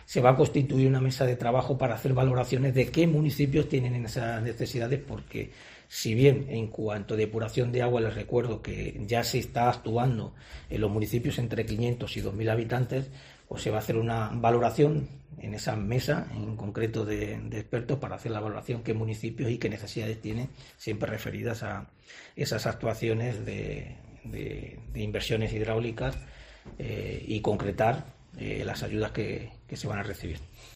El portavoz del Equipo de Gobierno, Juan Carlos Sánchez Mesón --ayuda-agua